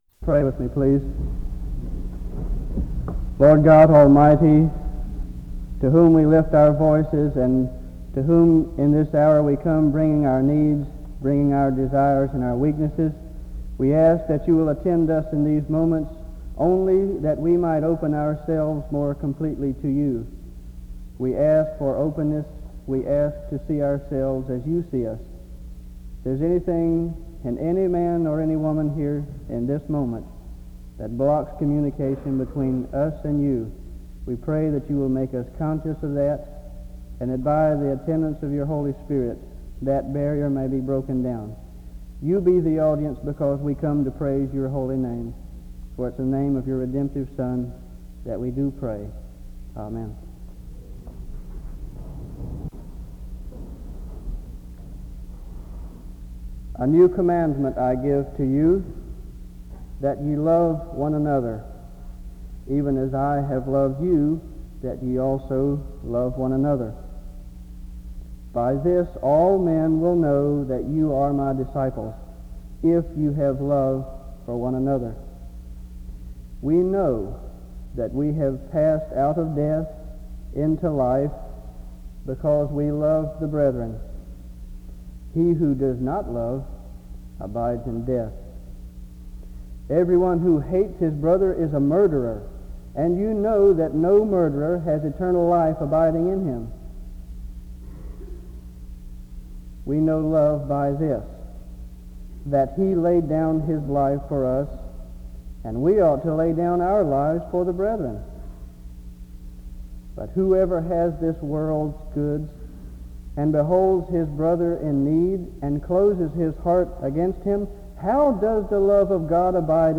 The service begins with a prayer from 0:00-0:50. Various portions of Scripture on the topic of love are read from 0:57-2:20.